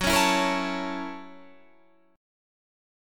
Gb7#9 chord